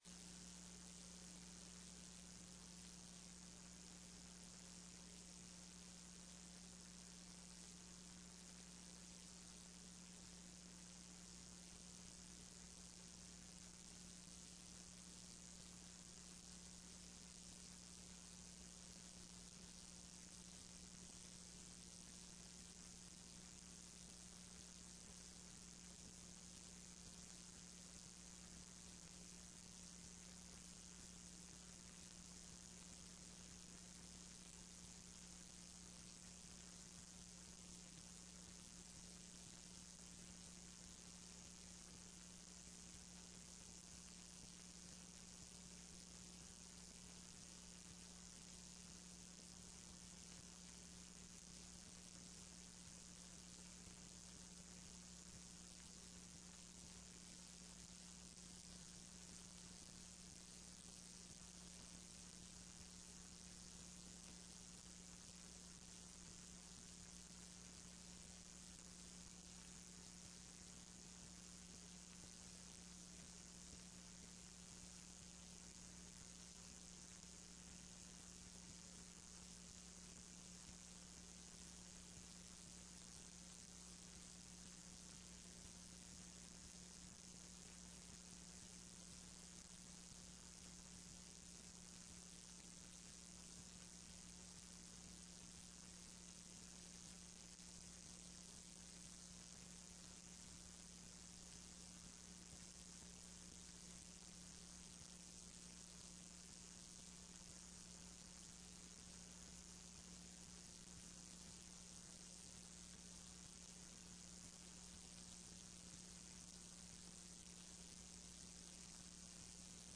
Sessão Plenária TRE-ES dia 10/02/15